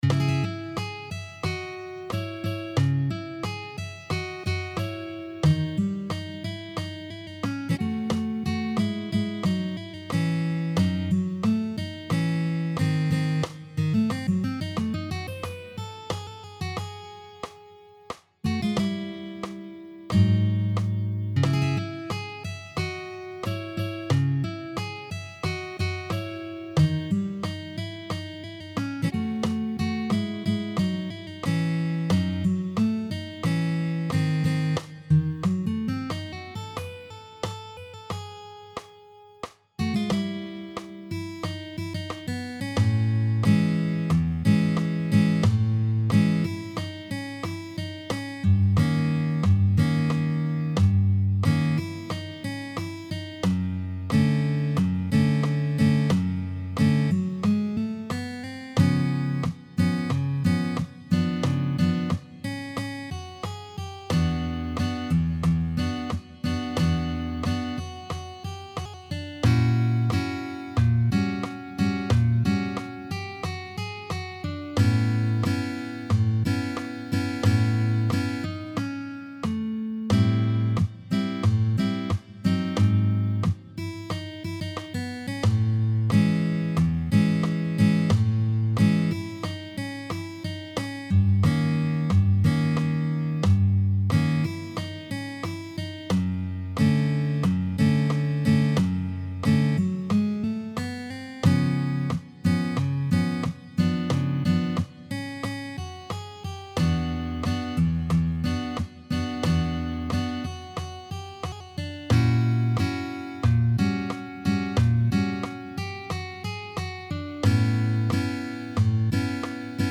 Versione solo guitar